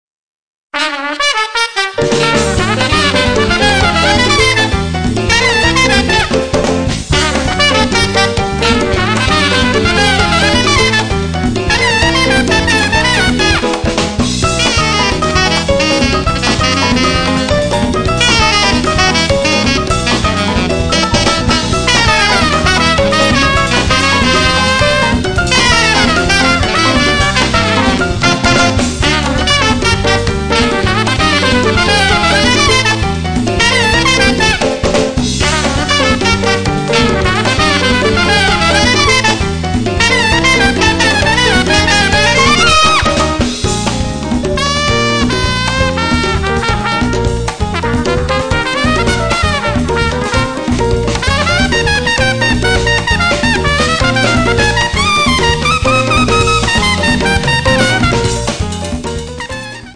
tromba, flicorno